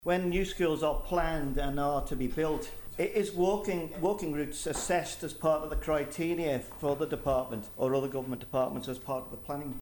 That's David Quirk - he raised the issue in the House of Keys this week with the Department of Education and Children.